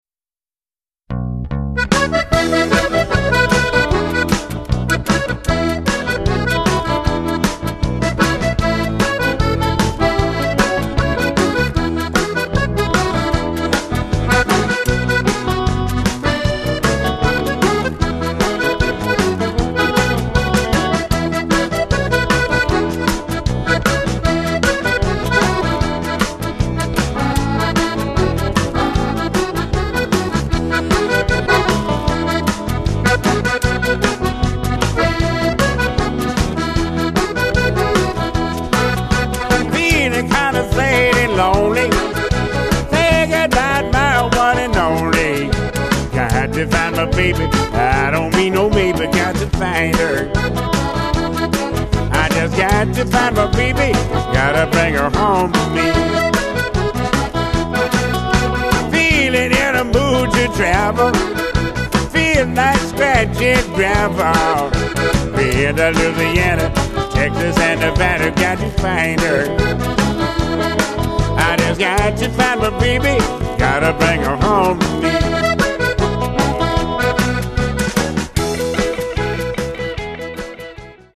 unique blend of Louisiana musical styles